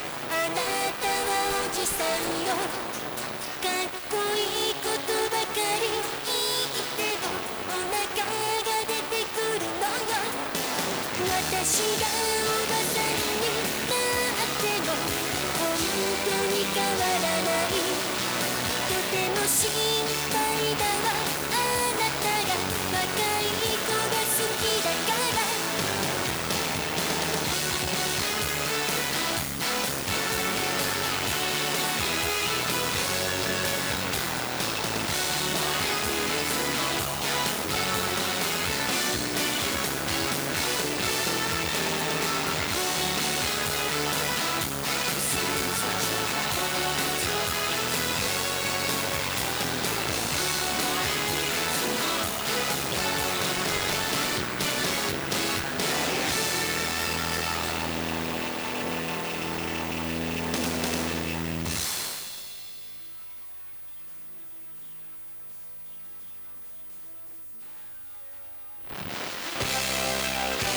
歪音が苦手な方は，録音データを再生しないようお願いいたします・・・。
27.9km　大高森_山頂_ＺＬスペシャル3素子アンテナ（93.5MHz用）
アンプを介さないイヤホンで　放送内容が十分わかる品質と音量が得られました